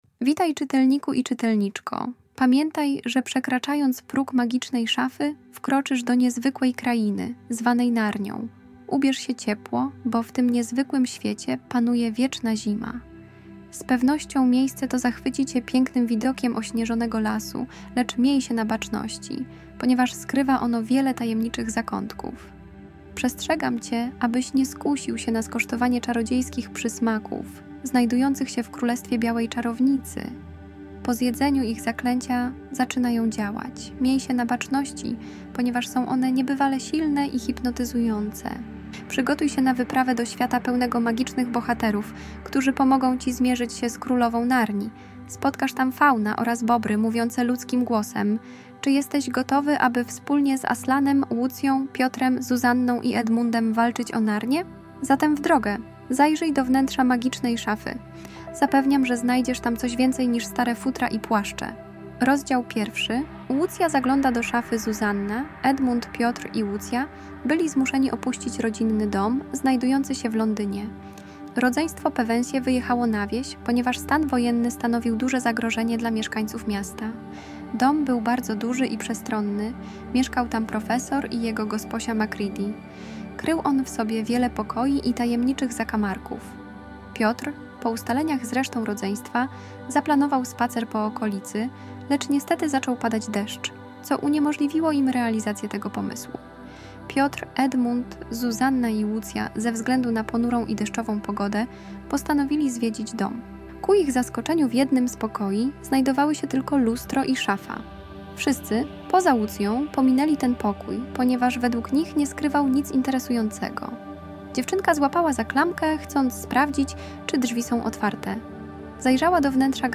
• Szata graficzna i muzyka zachęci Twoje dziecko do zapoznania się z treścią opracowania.
Opowieści z Narnii - Prezentacja multimedialna, audiobook, e -book